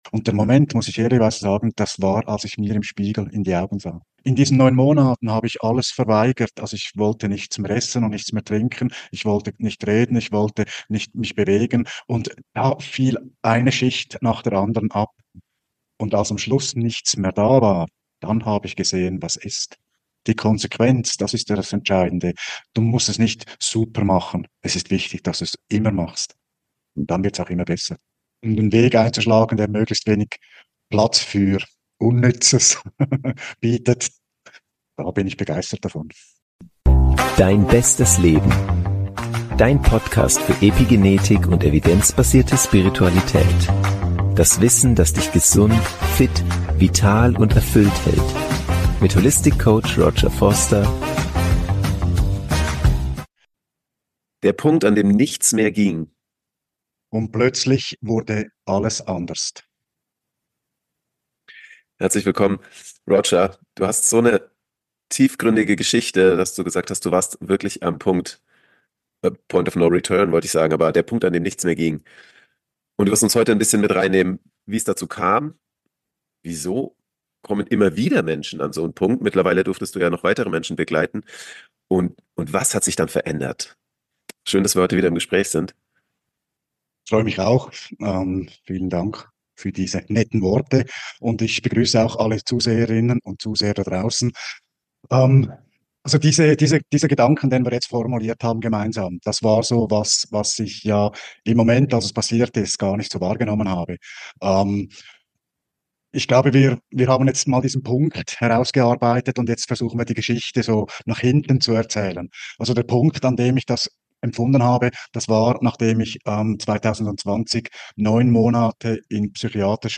Gespräch über Erinnerung & Transformation | Die unzerstörbare Kraft der Liebe ~ Dein bestes Leben: Evidenzbasierte Spiritualität und Epigenetik Podcast